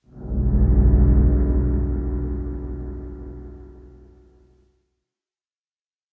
cave7.ogg